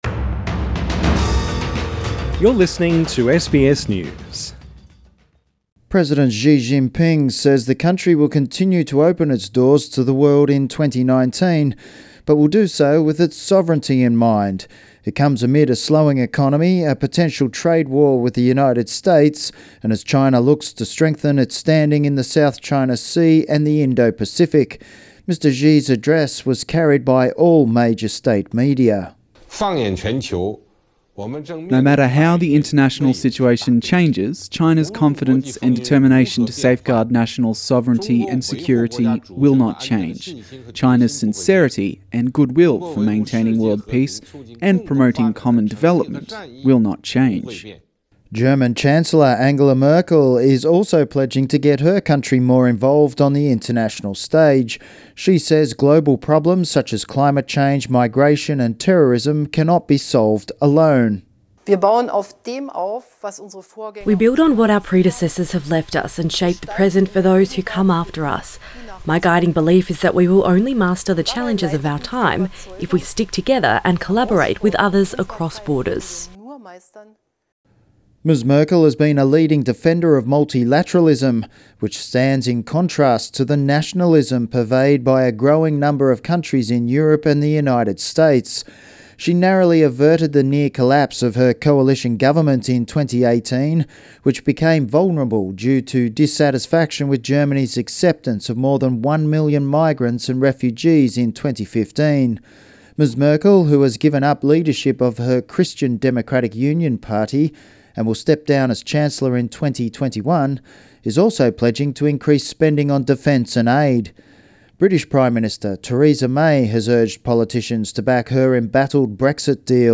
The world's leaders deliver their New Year's messages.